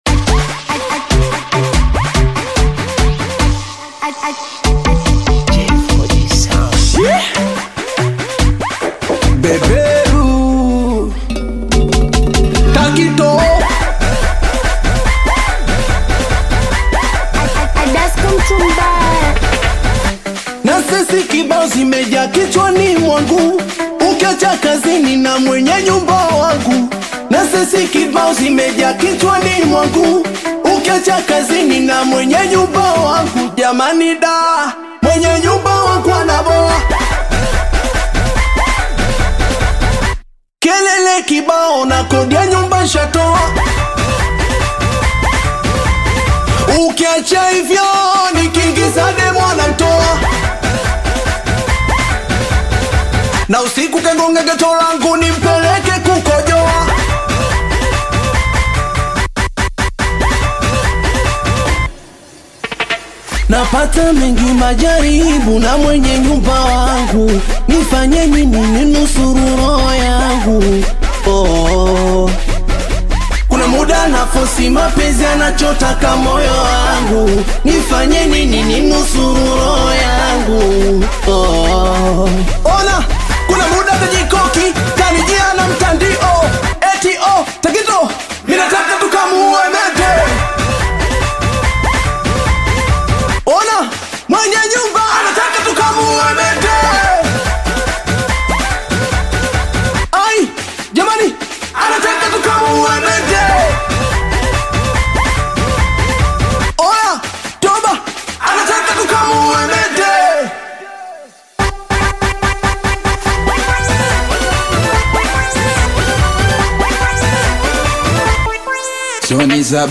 Singeli music track
Tanzanian Bongo Flava artists